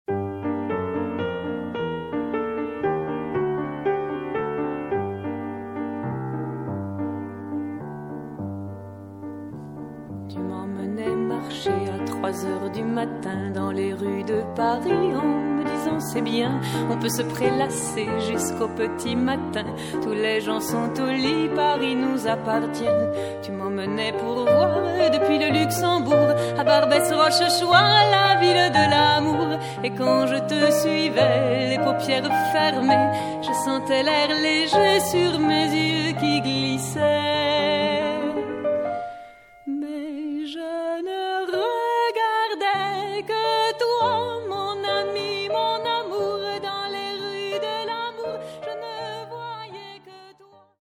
Piano/voix